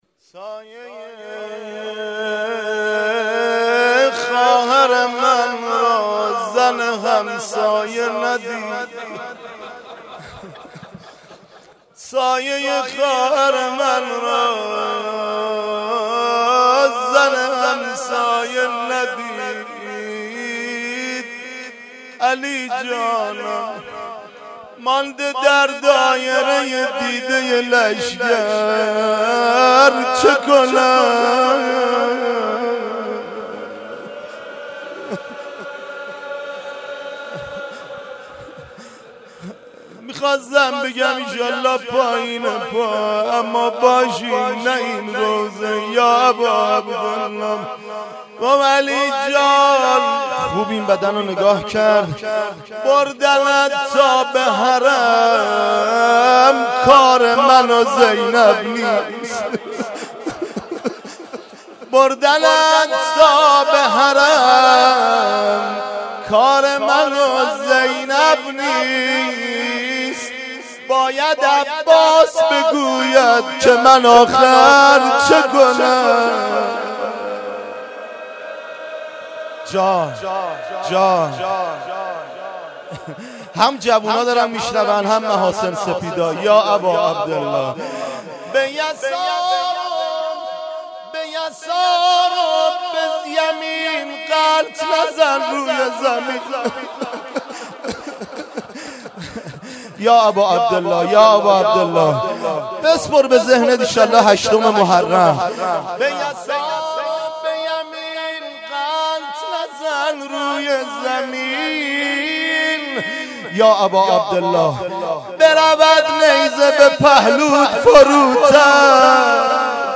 03.rozeh2.mp3